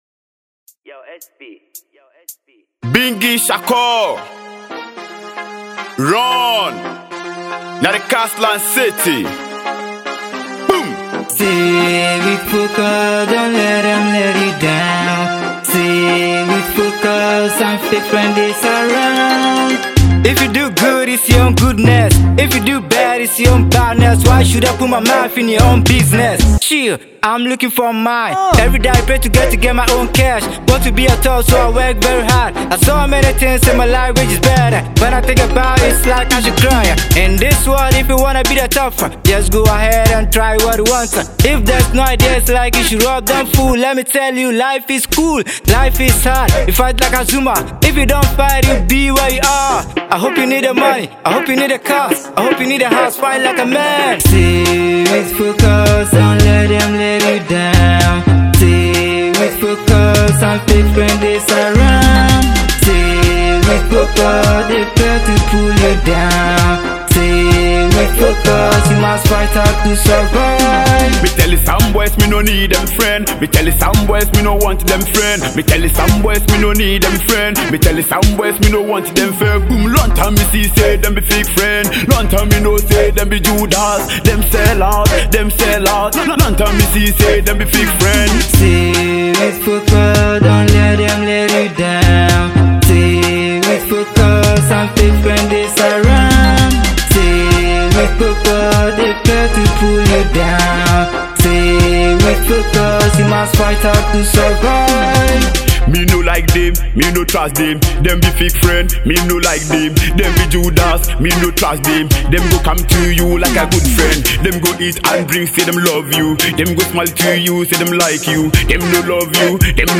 inspirational/motivational banger